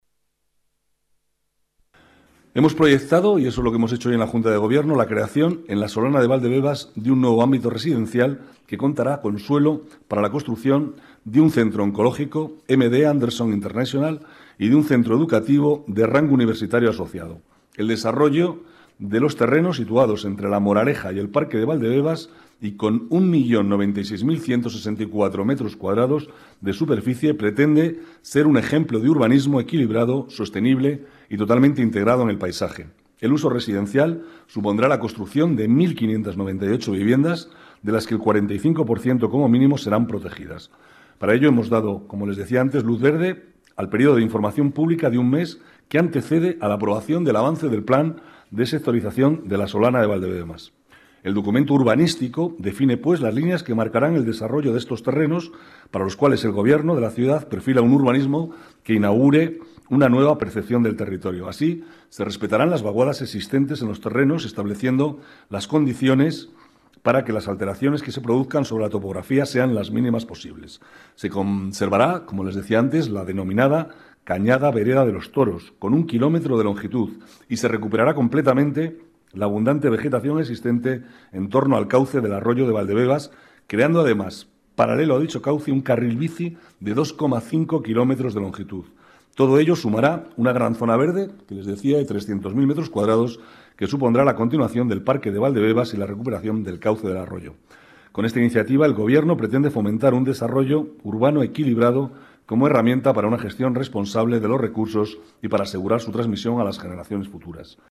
Nueva ventana:Declaraciones del vicealcalde, Manuel Cobo: un Centro Oncológico en Valdebebas